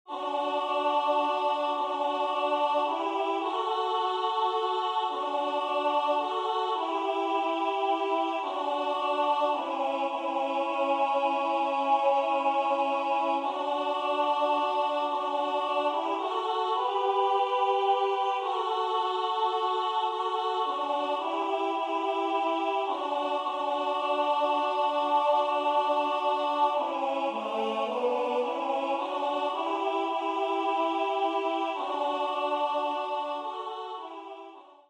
ENSEMBLE (Chorale)